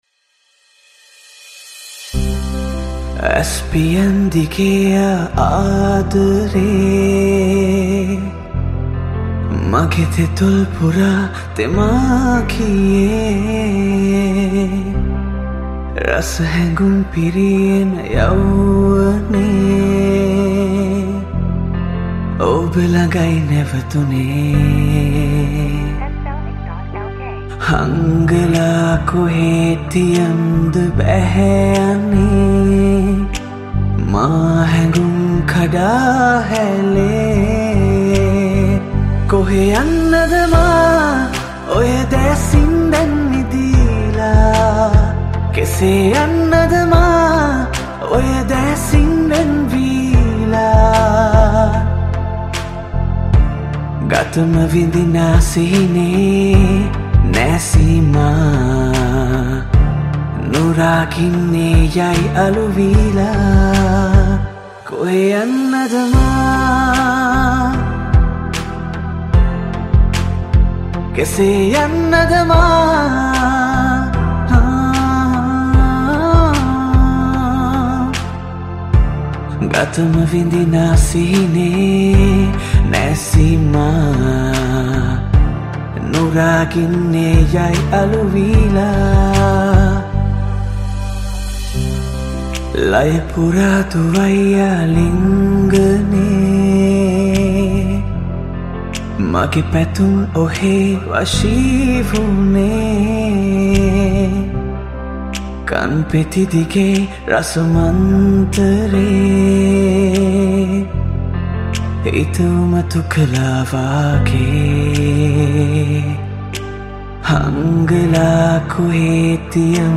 Cover Song